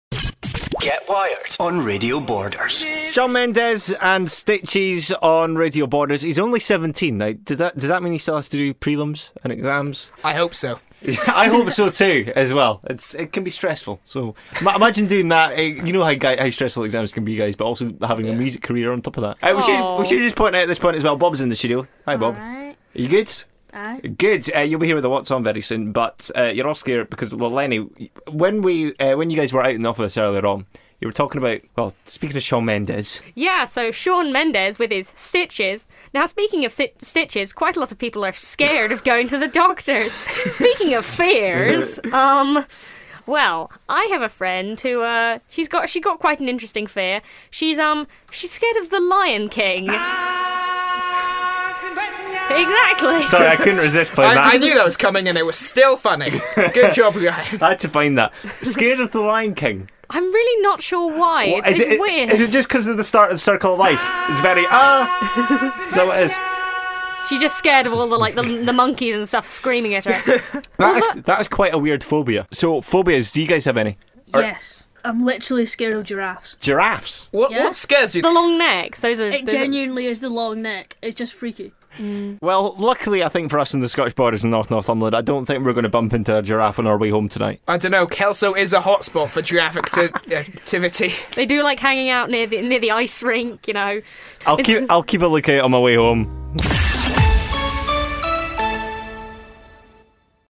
A conversation about Shawn Mendes and his 'Stiches' lead to a conversation about Phobias! Within 2 minutes, we learnt of a few strange ones!